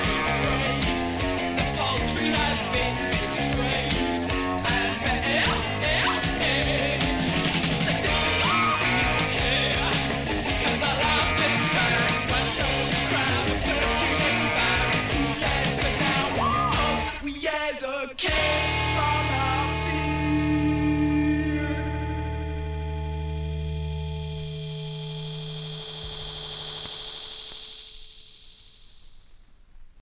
saxofone
Som claramente - diríamos até orgulhosamente - datado